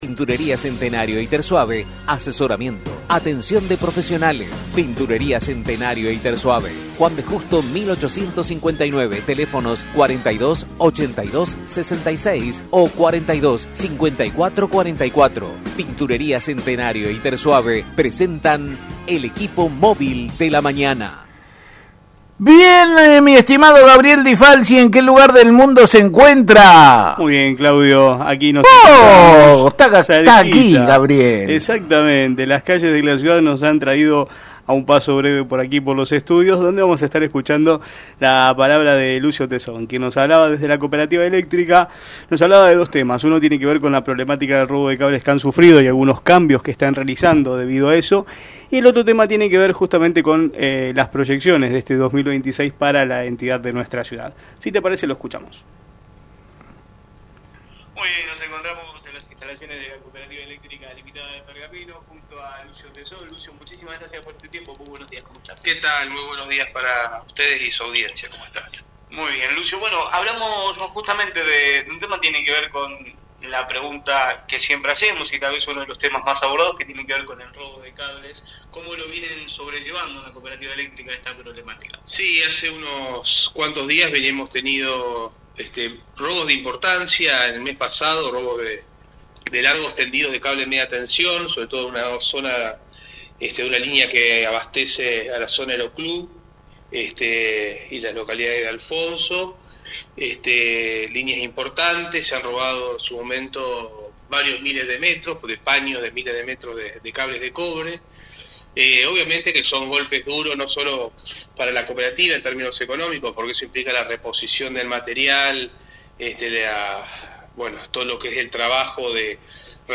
La Mañana de la Radio
Uno de los temas centrales de la entrevista fue la problemática del robo de cables, una situación que viene afectando seriamente a la entidad.